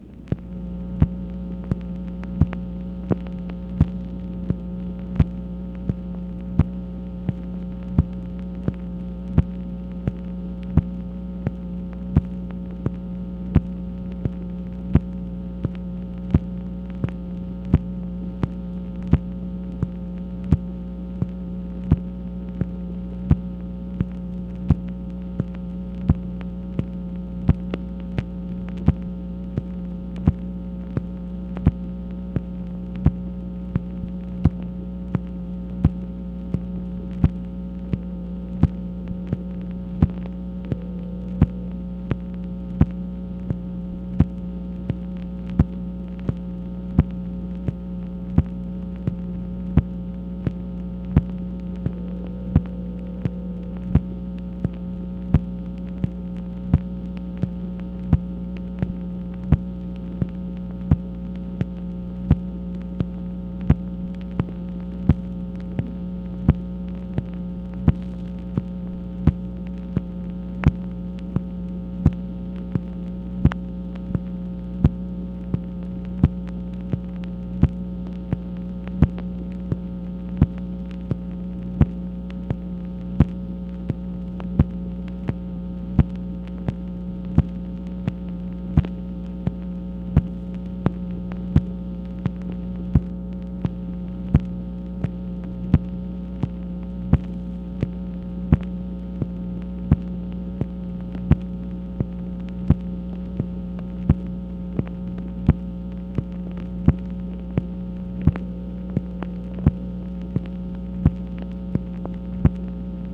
MACHINE NOISE, September 12, 1966
Secret White House Tapes | Lyndon B. Johnson Presidency